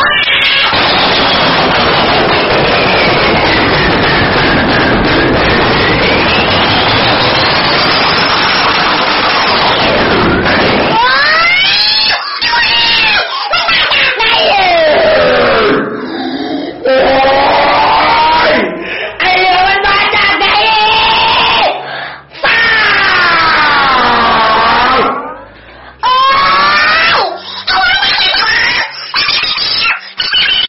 หมวดหมู่: เสียงเรียกเข้า
ริงโทนเสียงปลุกแบบรีมิกซ์